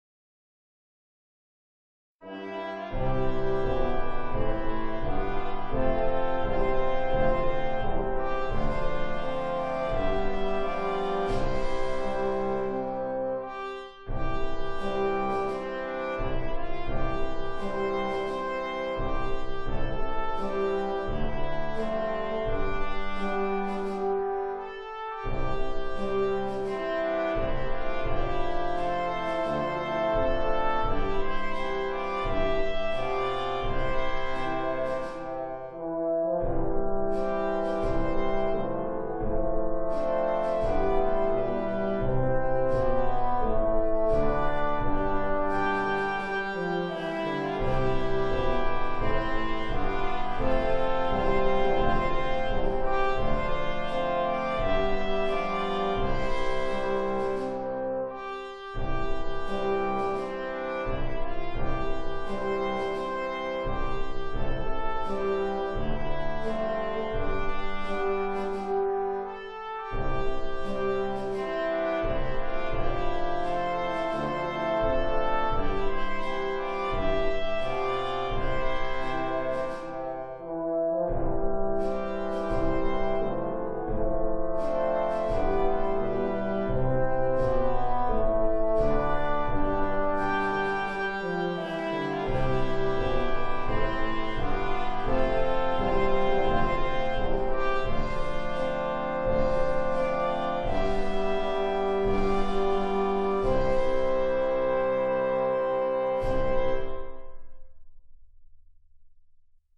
「金管バージョン」
kohokubrass.wma